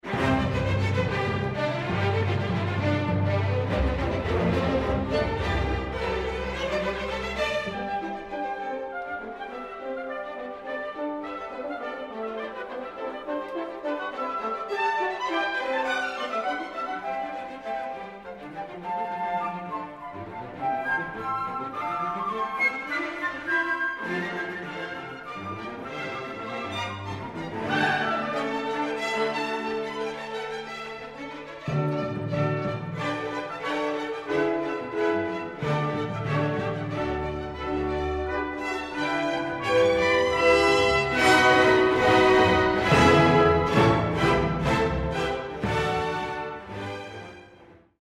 Finał jest krzykliwy, szybki i mechaniczny.
Tego typu niepotrzebnie brutalnych odcinków jest znacznie więcej: